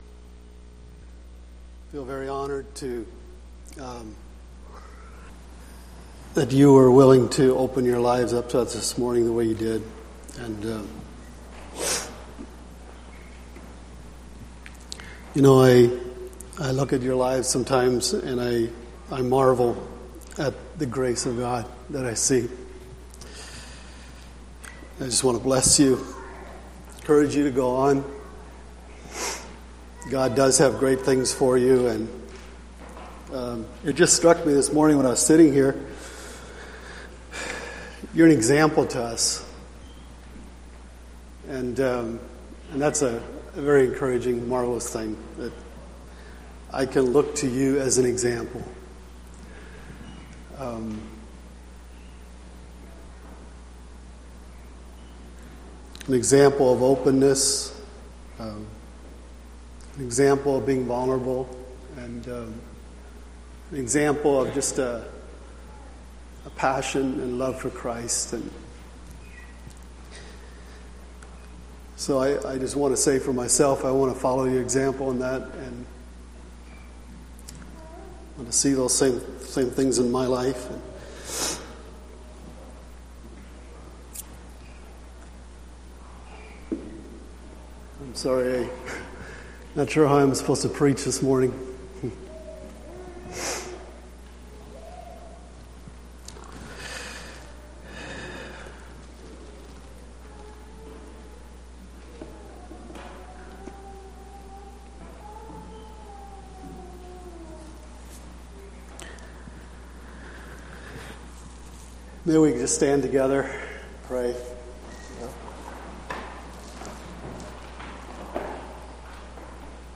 Sunday Morning Sermon Service Type